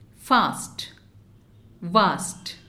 English: Minimal pairs involving consonant sounds:-fast-vast